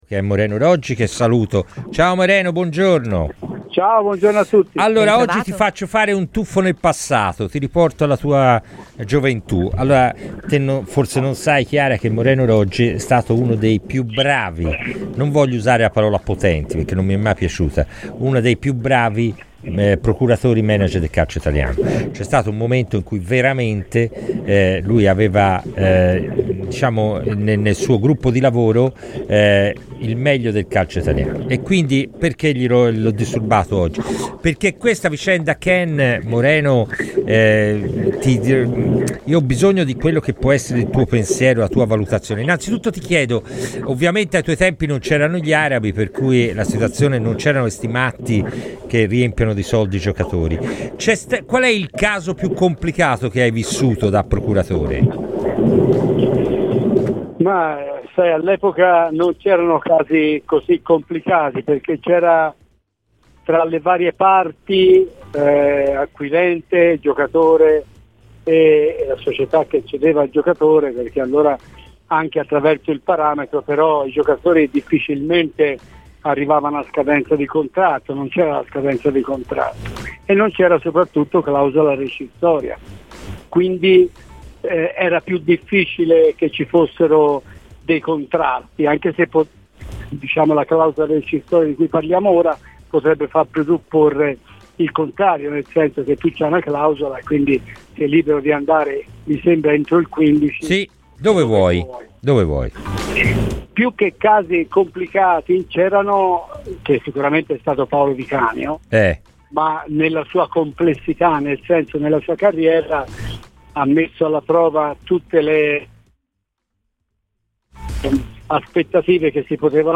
A scolta il podcast per l'intervista completa!